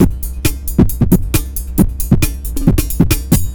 ELECTRO 15-L.wav